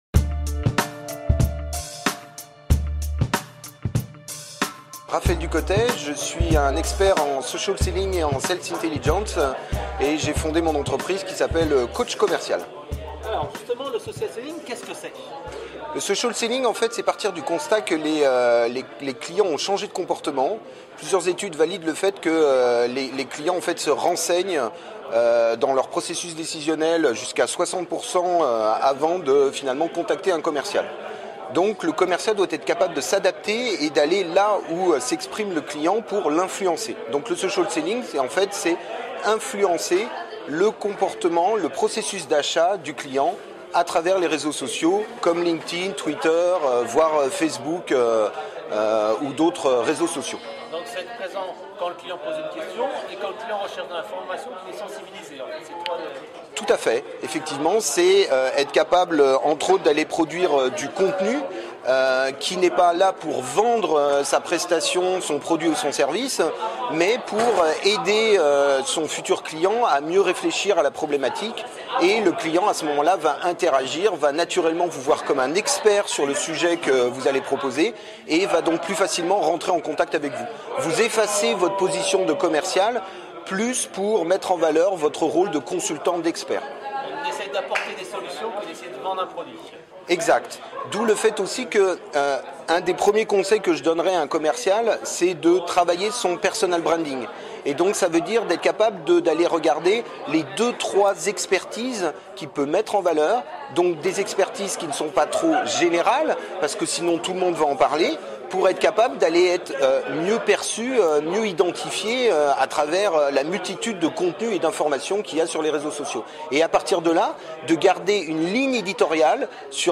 Dans cette interview vous allez trouver des conseils pour trouver des clients en B2B via le Social Selling ?